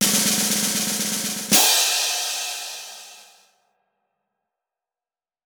Redoble de tambores de circo
tambor
redoble